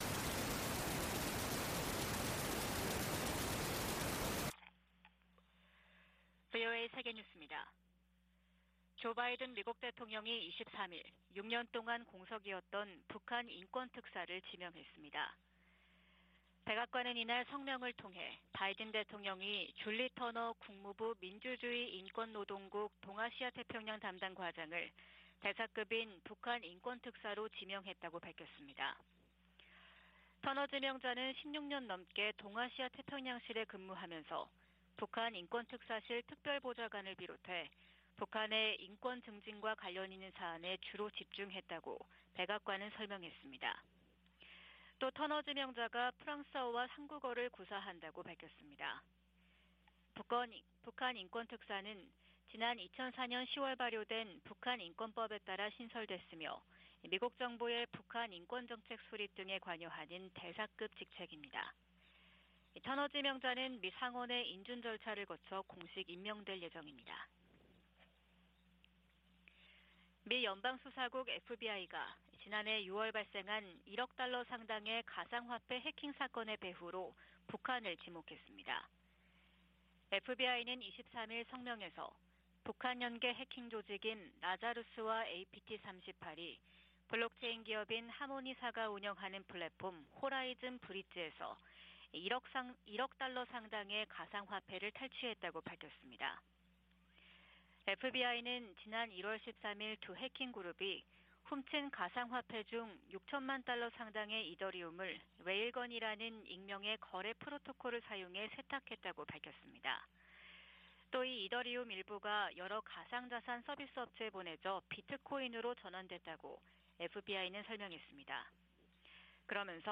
VOA 한국어 '출발 뉴스 쇼', 2023년 1월 25일 방송입니다. 국무부는 북한과 러시아 용병 회사 간 무기거래와 관련해 한국 정부와 논의했다고 밝혔습니다. 북한에서 열병식 준비 정황이 계속 포착되는 가운데 평양 김일성 광장에도 대규모 인파가 집결했습니다.